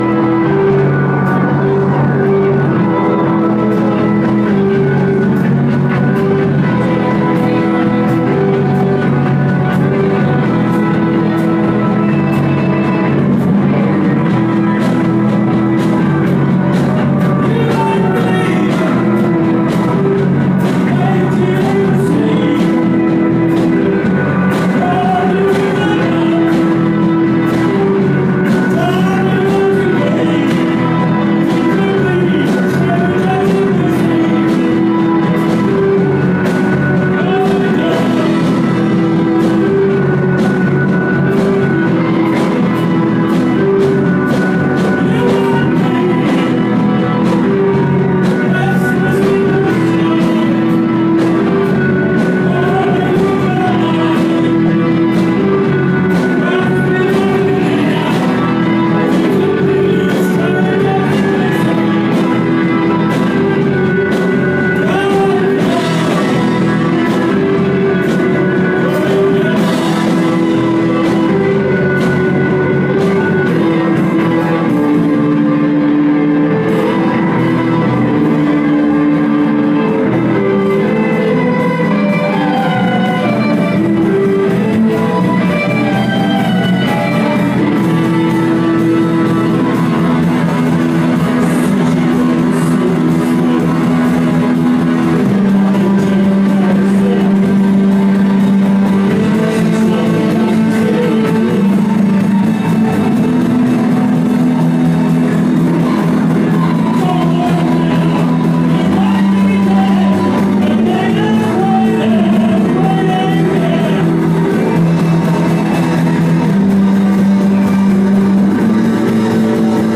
live at Ravensbourne Maori Masonic Lodge last night